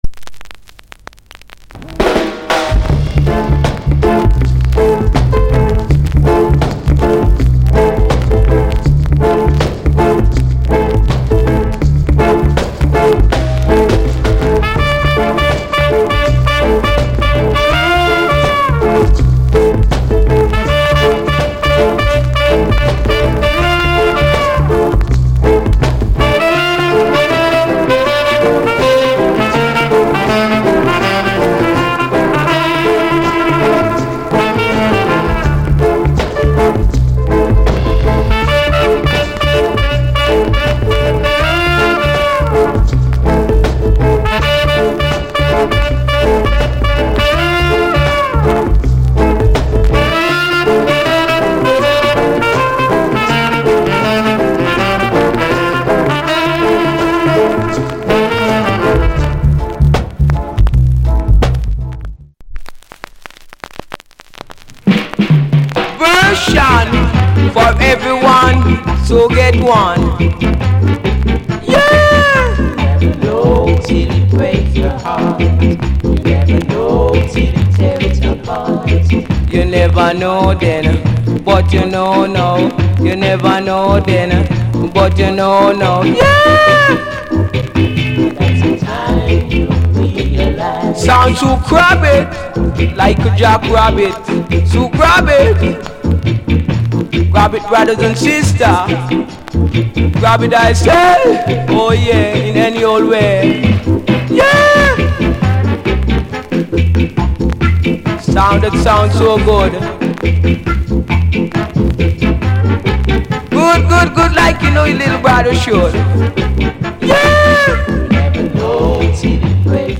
Genre Rock Steady / Inst